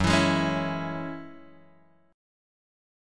F#7sus2 chord {2 x 2 1 2 0} chord